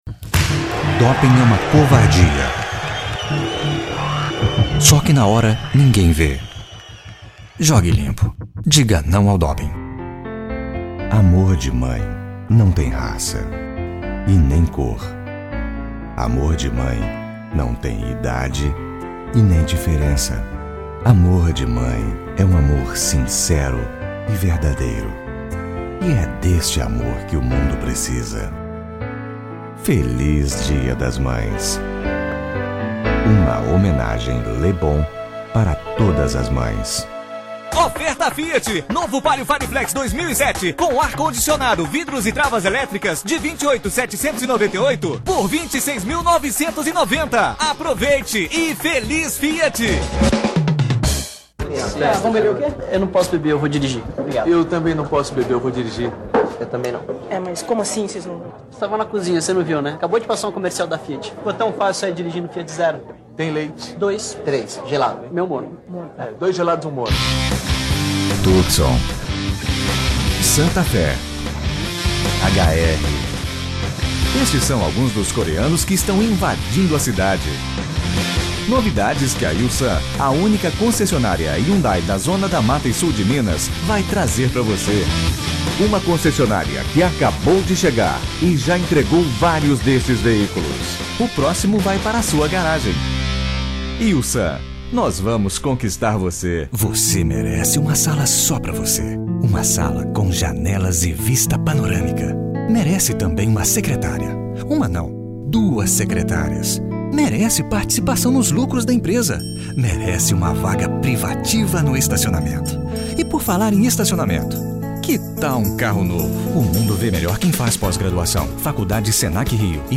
Sprechprobe: Werbung (Muttersprache):
Portuguese voice over artist.